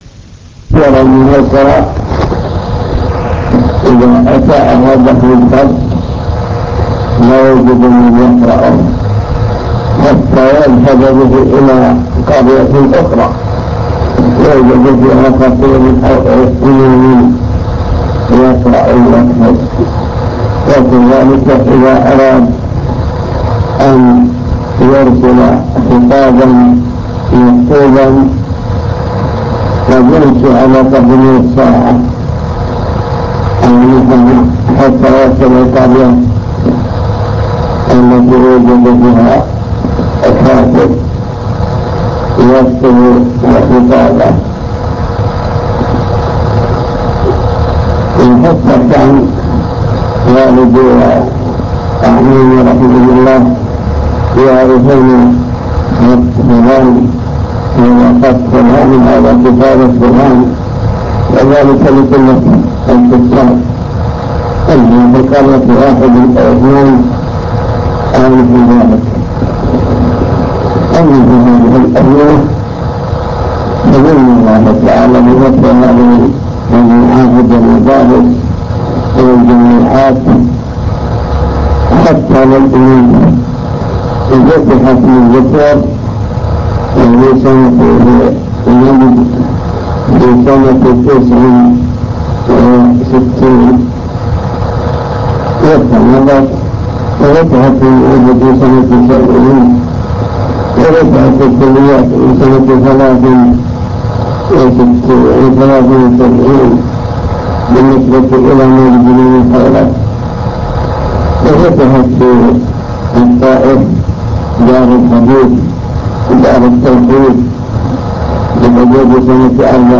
المكتبة الصوتية  تسجيلات - لقاءات  لقاء مكتب الدعوة